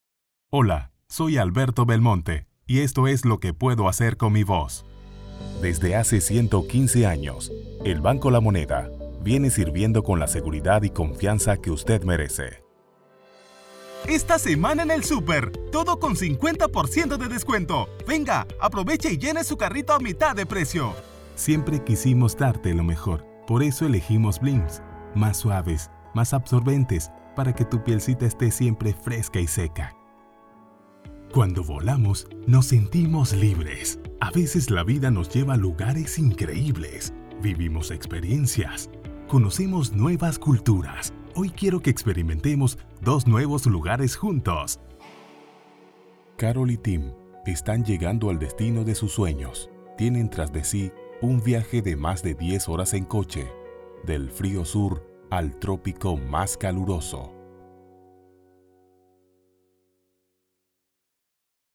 soy locutor comercial con 7 años de experiencia
Sprechprobe: Werbung (Muttersprache):